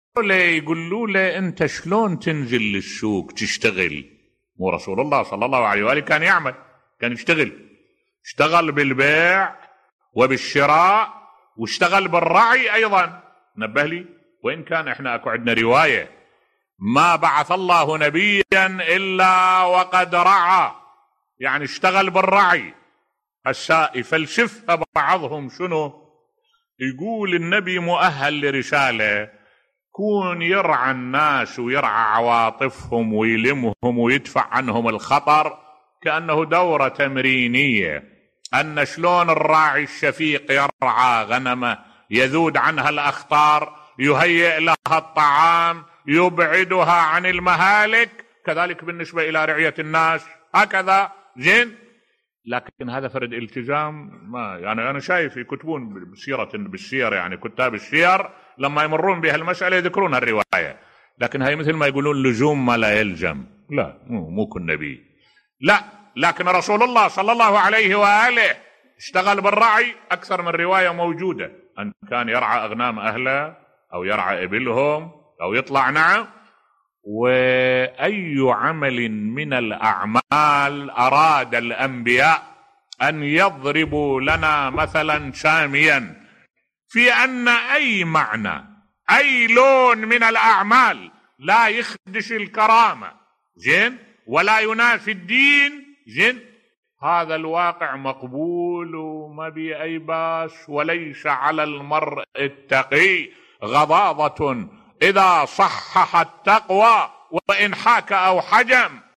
ملف صوتی النبي محمد (ص) كان يعمل و يأكل من كد يده بصوت الشيخ الدكتور أحمد الوائلي